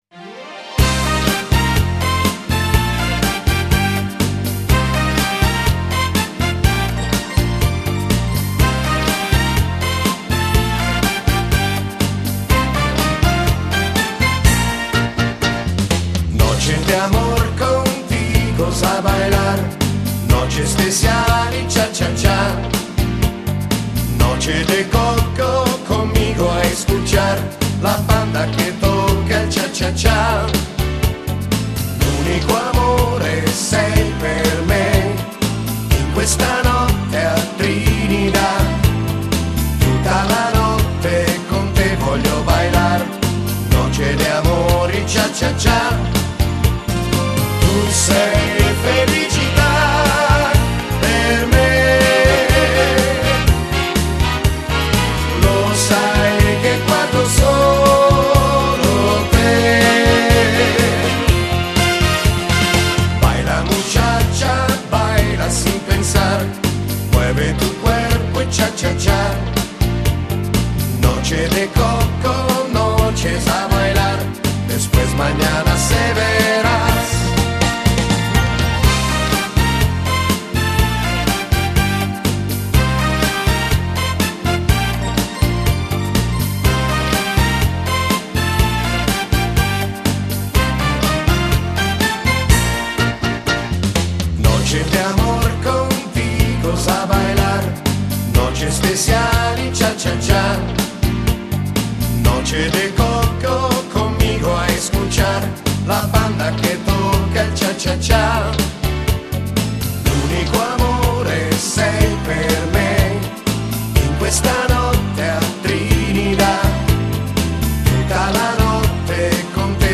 Genere: Cha cha cha